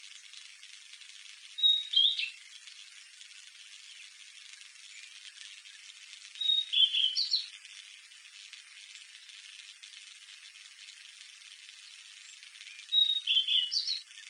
Rusty tailed flycatcher